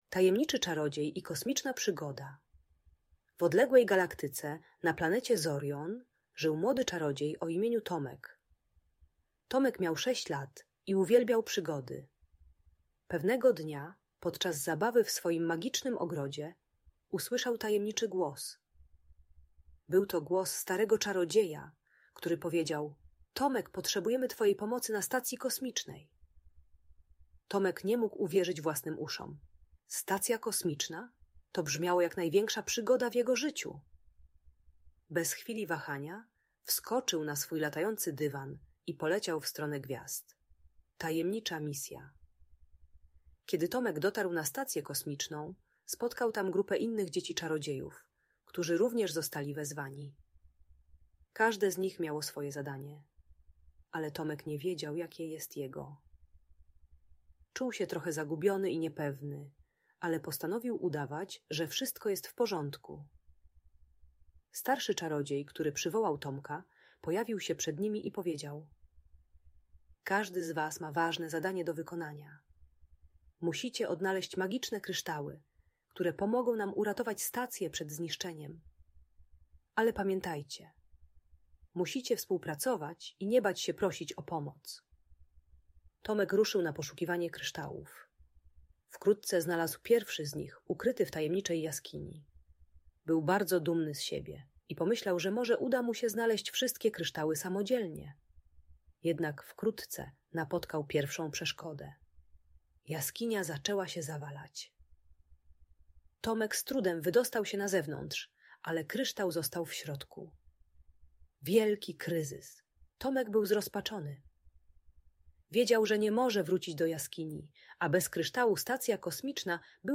Tajemniczy Czarodziej i Kosmiczna Przygoda - Magiczna historia - Audiobajka